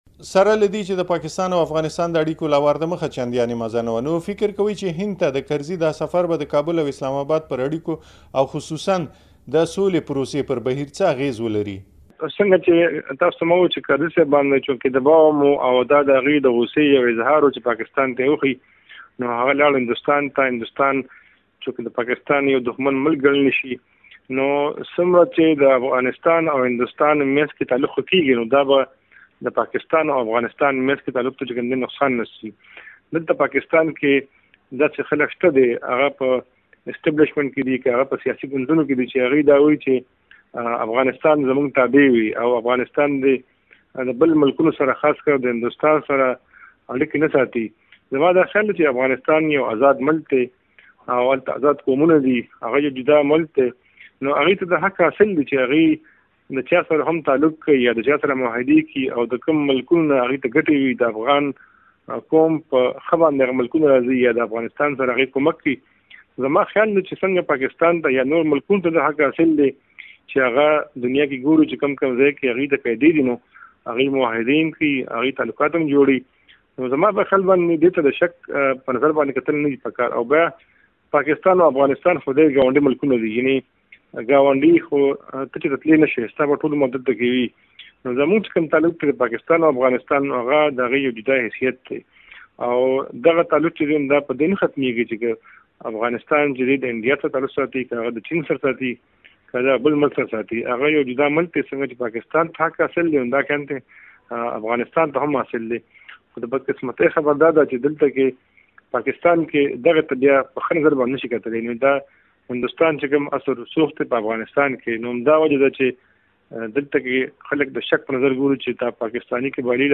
مریکه